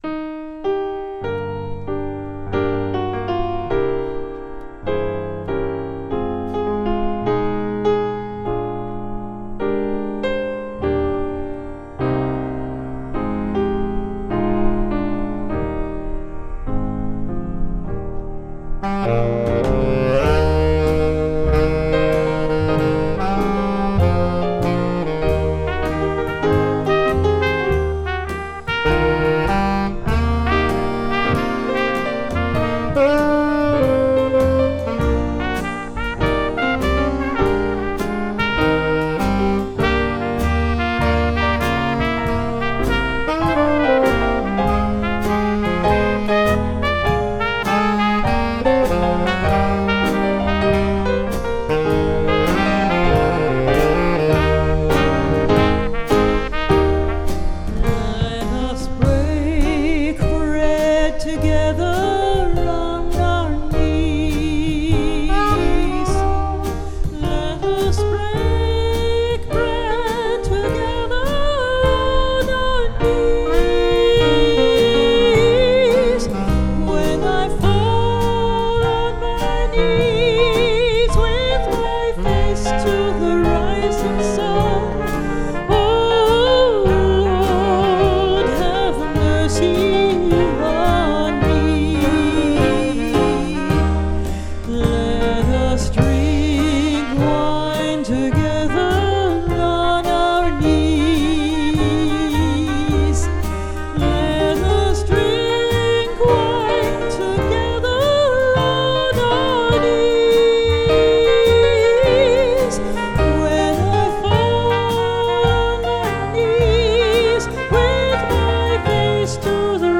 Summer Ecumenical Service
You can also enjoy these beautiful performances from the Occasional Jazz Ensemble, recorded during the service.